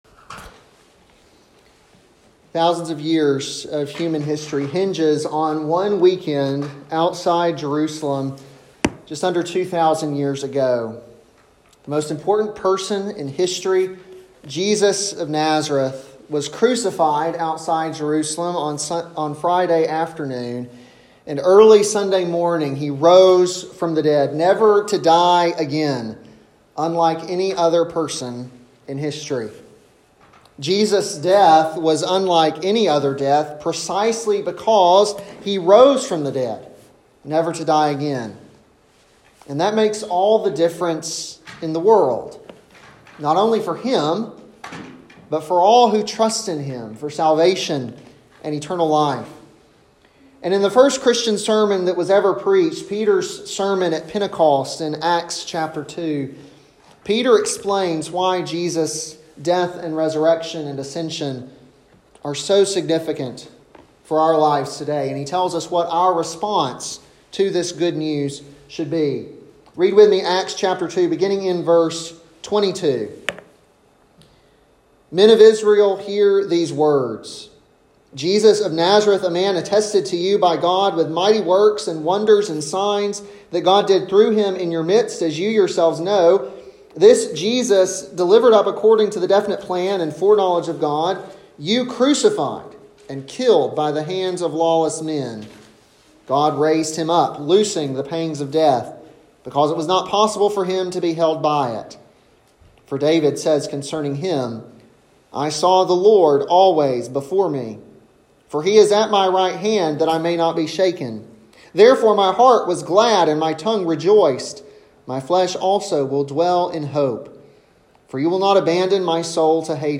Easter 2020 sermon on Acts 2:22-41